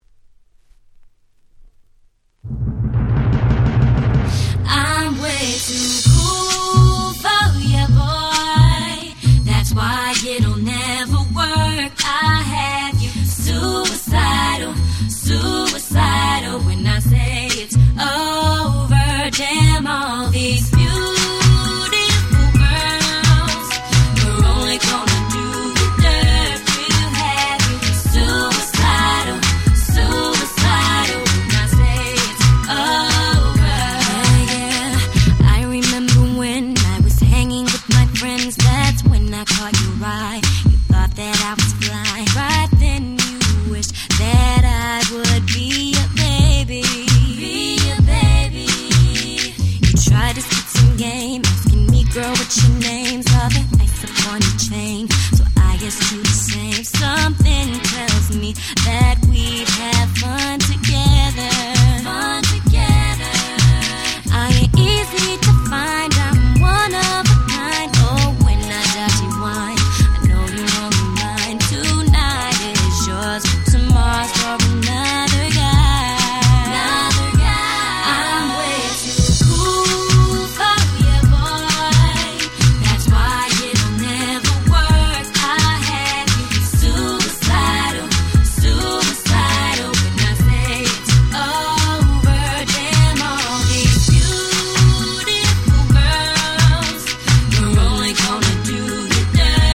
00's R&B